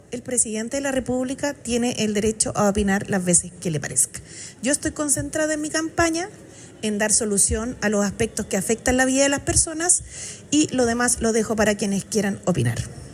Asimismo, tras participar de un conversatorio en la Universidad del Desarrollo, la Jeannette Jara fue consultada por la “incomodidad” que han manifestado sus voceros.